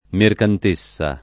[ merkant %SS a ]